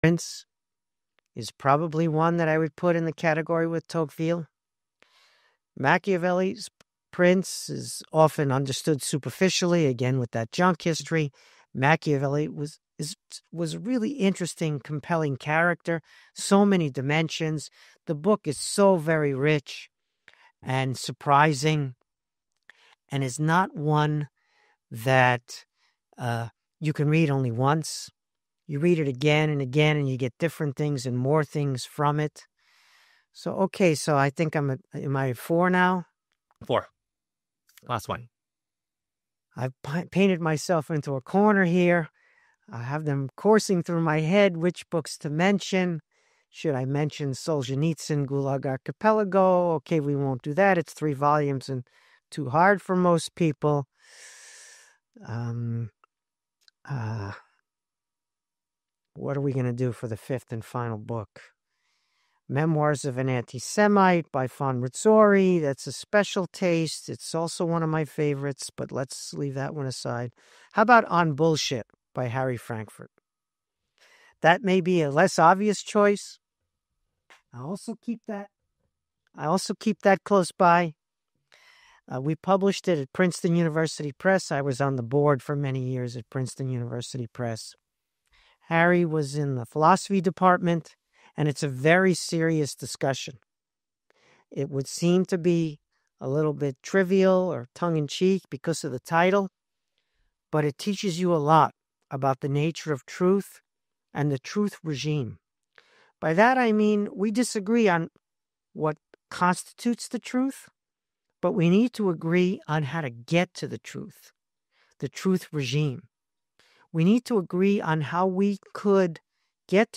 I like his accent and his Joe Pesci voice (which even he jokes about.) Anyway, I came across a YouTube video of a Kotkin  conversation — How Historians Work: A History Lab Discussion with Dan Wang and Stephen Kotkin | Hoover Institution.